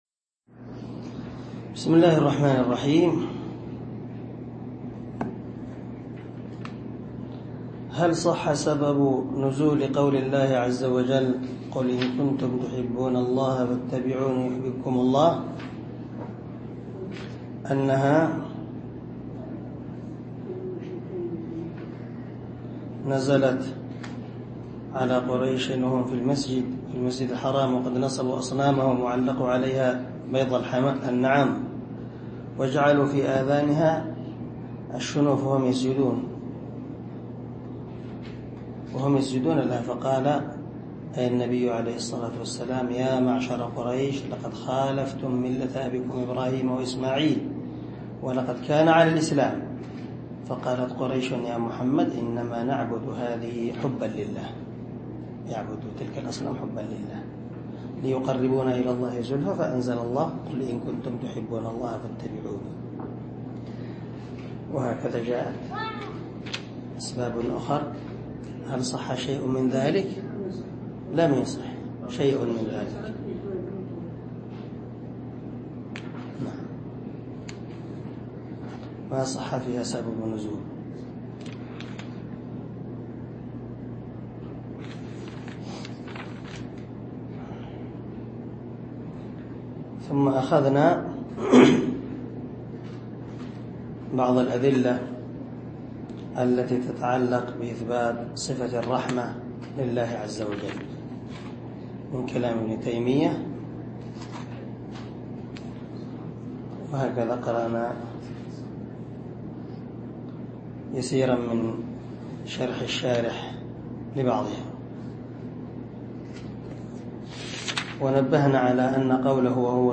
شرح العقيدة الواسطية للعلامة محمد بن خليل هراس رحمه الله – الدرس الثاني والعشرون